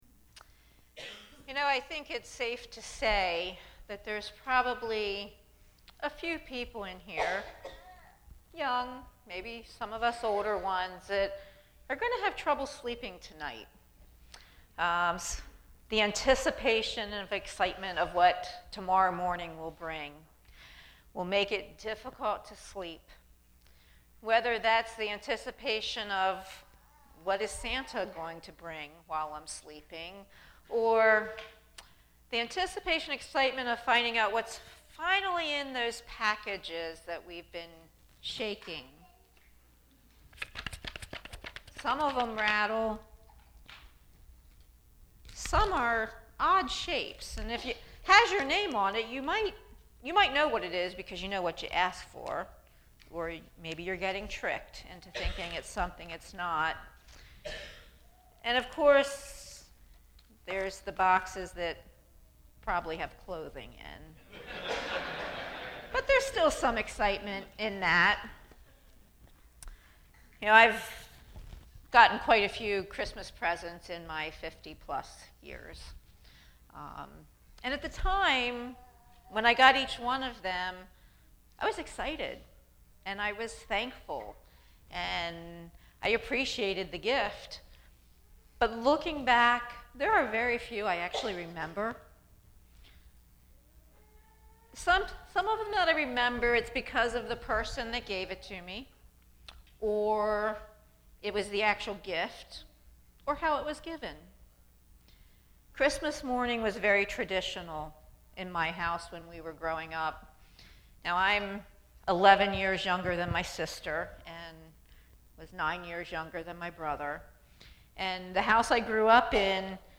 Christmas Eve 2018